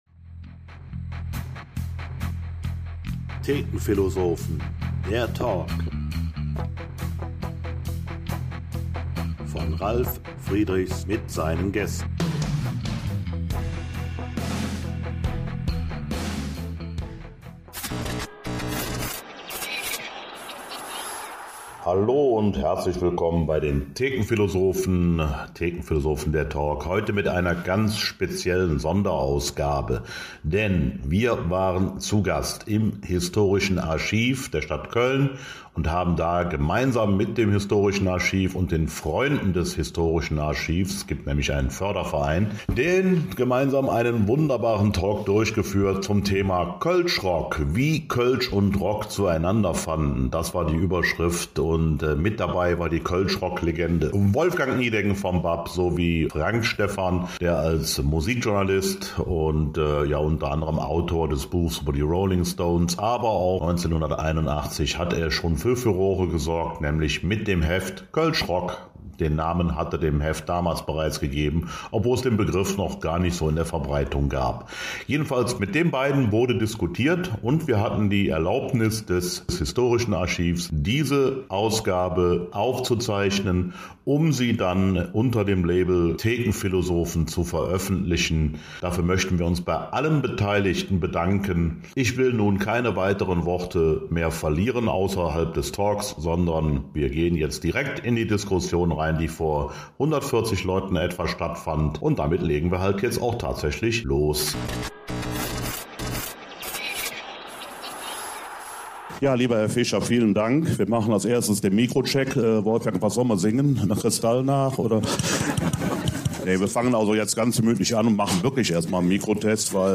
Thekenphilosophen unterwegs: Das Historische Archiv Köln lud gemeinsam mit dem Förderverein FREUNDE des Historischen Archivs der Stadt Köln e.V. am 12.04.2023, 18 Uhr, zu einer prominent besetzten Diskussionsrunde ein.
Mit der Erlaubnis unserer Gastgeber vom Historischen Archiv dürfen wir diesen Talk nun unter dem Dach der Thekenphilosophen präsentieren.